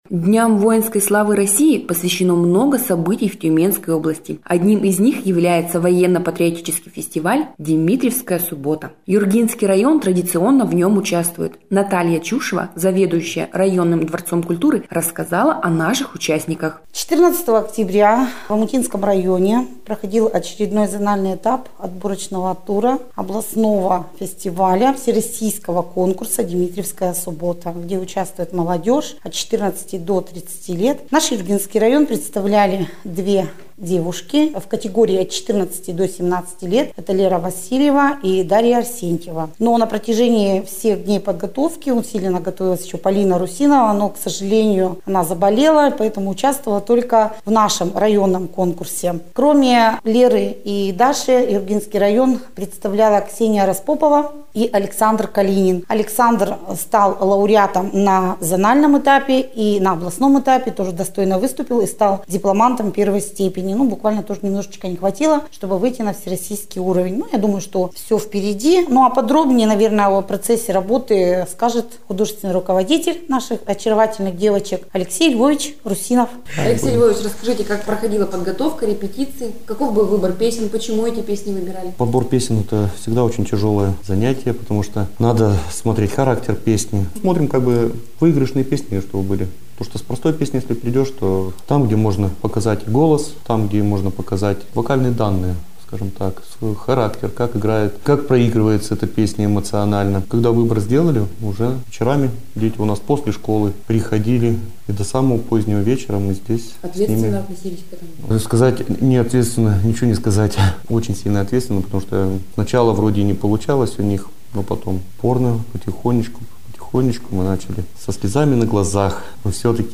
О юргинцах – участниках фестиваля "Димитриевская суббота" – в радиосюжете "Юргинской волны".